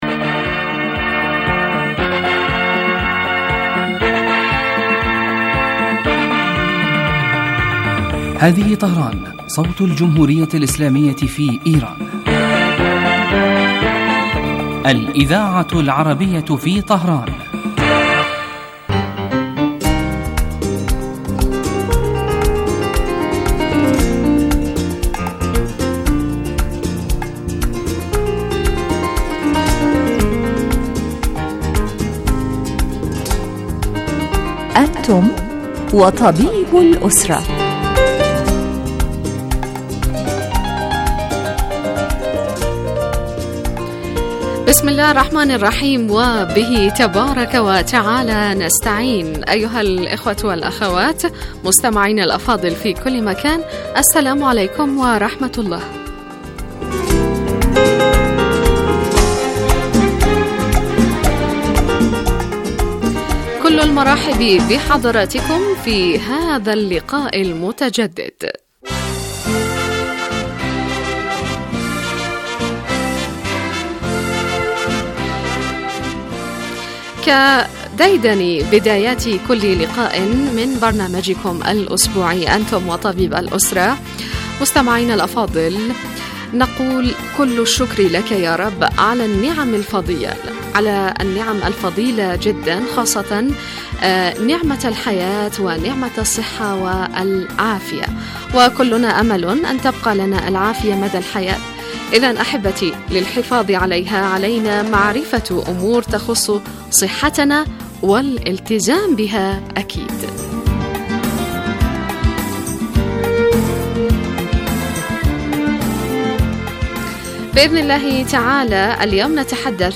يتناول البرنامج بالدراسة والتحليل ما يتعلق بالأمراض وهو خاص بالأسرة ويقدم مباشرة من قبل الطبيب المختص الذي يرد كذلك علي أسئلة المستمعين واستفساراتهم الطبية